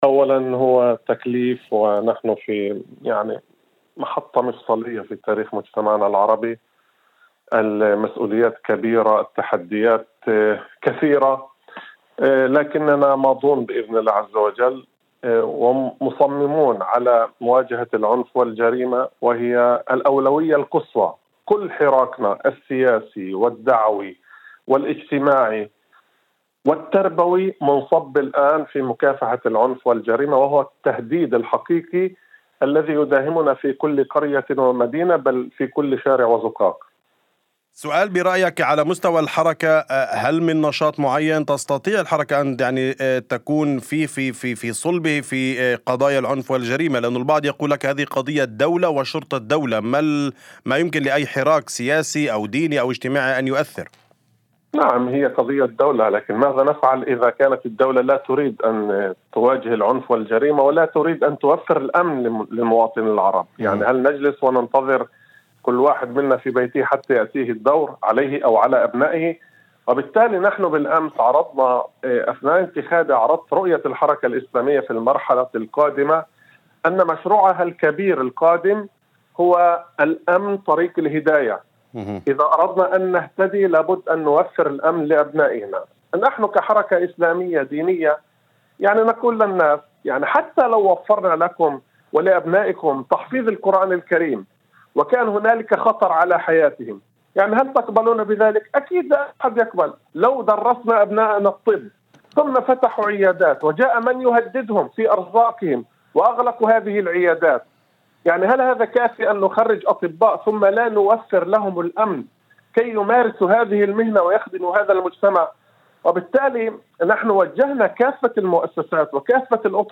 وفي مداخلة لإذاعة الشمس عقب انتخابه